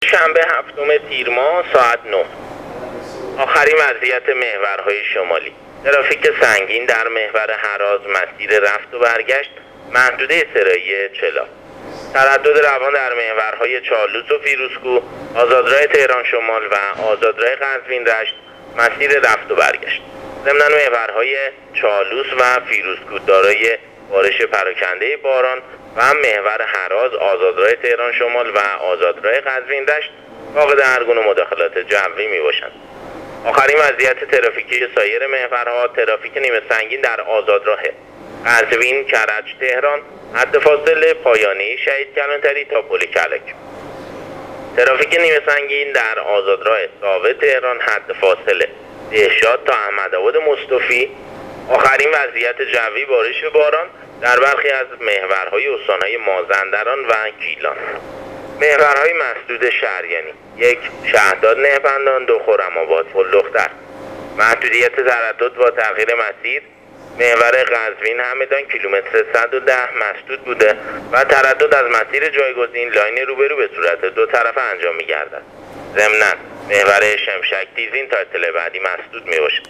گزارش رادیو اینترنتی از وضعیت ترافیکی جاده‌ها تا ساعت ۹ شنبه ۷ تیر